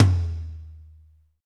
Index of /90_sSampleCDs/Northstar - Drumscapes Roland/DRM_Pop_Country/KIT_P_C Wet 1 x
TOM P C L11R.wav